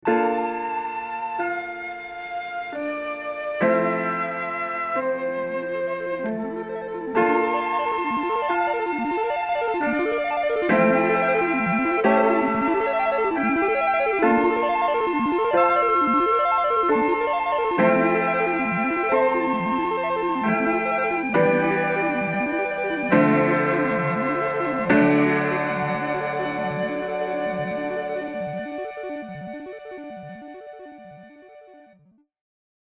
Note: In these examples, Version A is with no effect, Version B is with a short decay, and Version C is with a much longer decay.
Keys-No-Capitol-Chambers.mp3